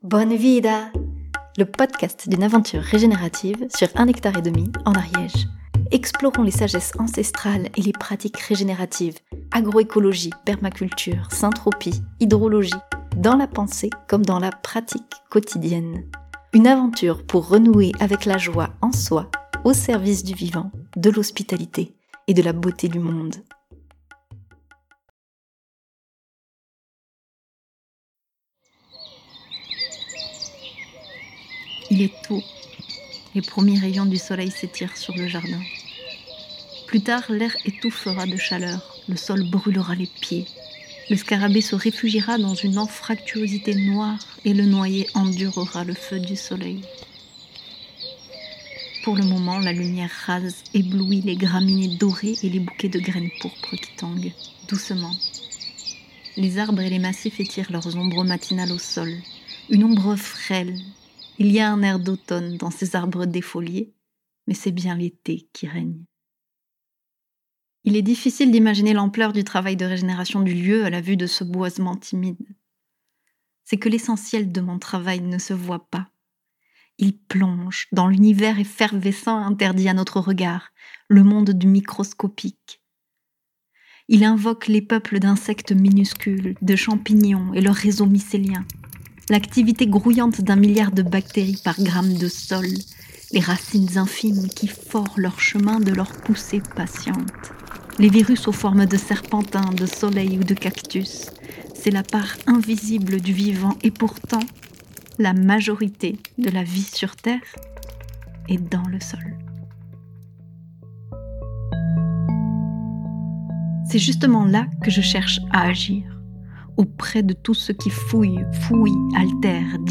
Une première visite sonore et sensorielle du jardin à l'occasion du chantier régénératif de l'année 2025 : un grand semis de graines récoltées et enrobées d'argile selon la méthode de Fukuoka. Exploration de la récolte des graines, et d'une voie d'interaction avec le monde.